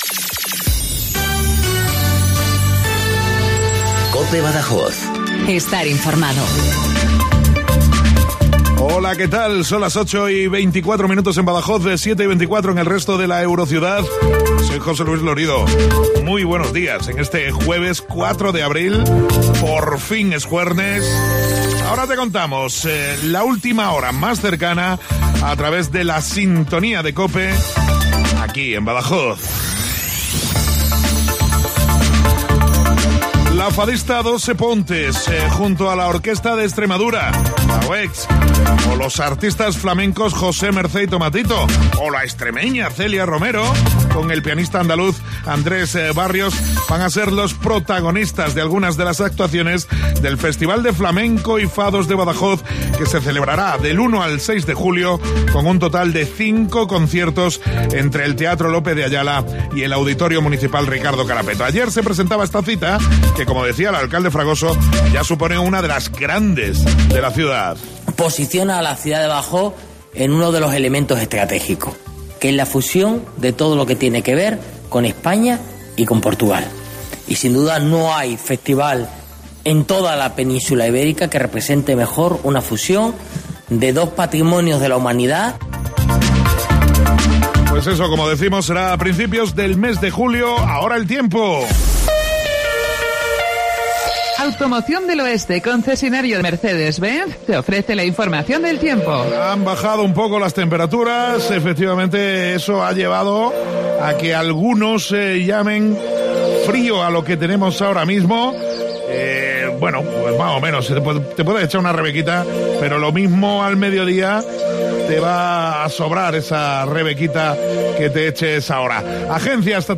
INFORMATIVO LOCAL BADAJOZ 0824